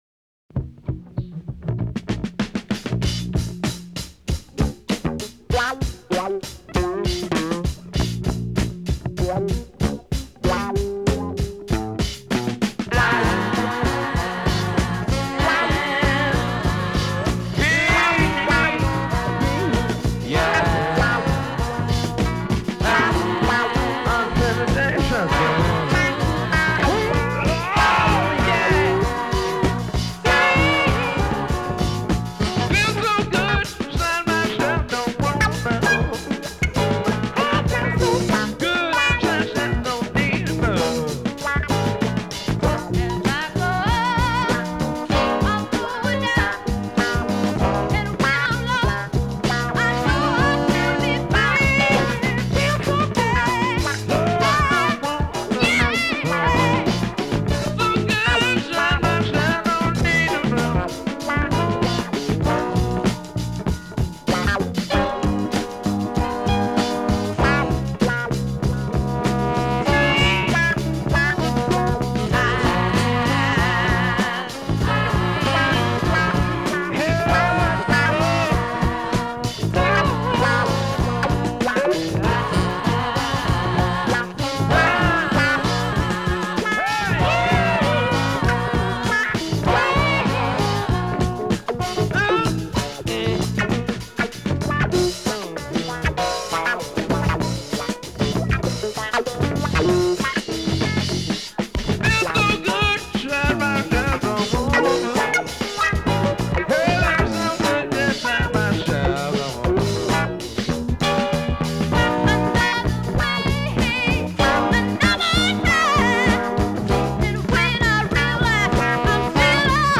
The group sound as tight and together as ever.